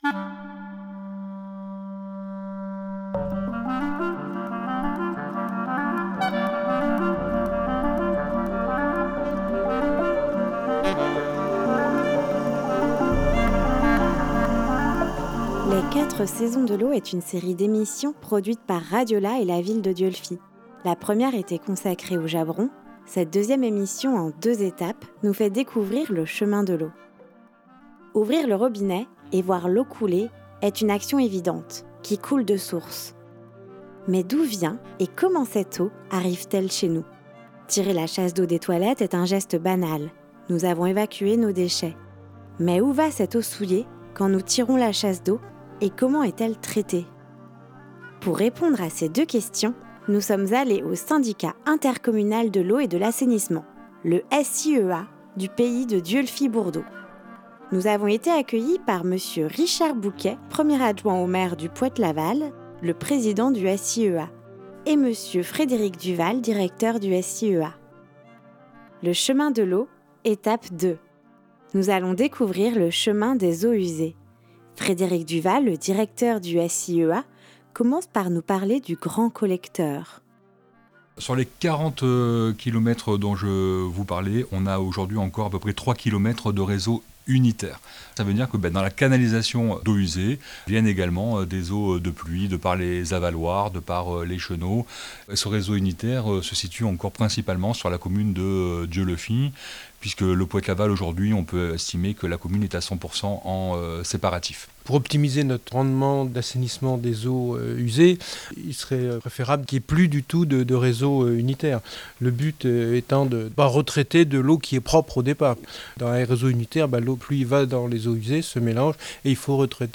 19 avril 2023 12:21 | Au fil de l'eau, emissions, reportage, territoire